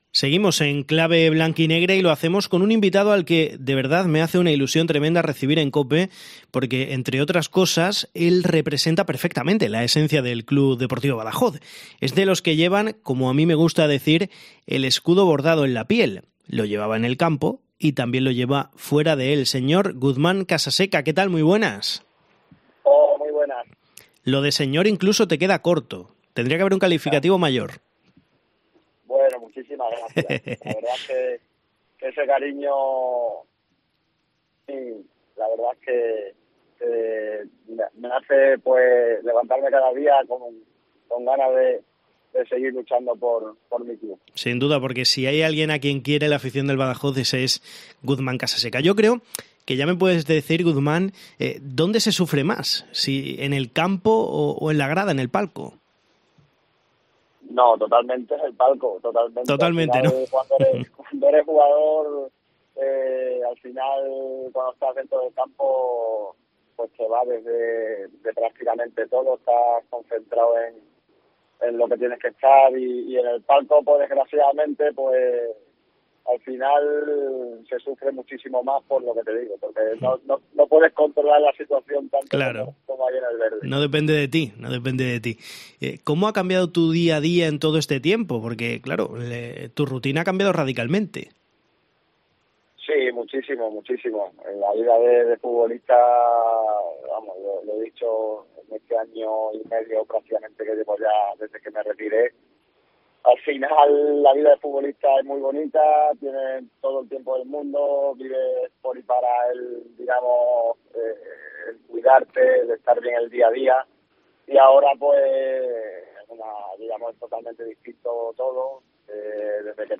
Su entrevista, en titulares .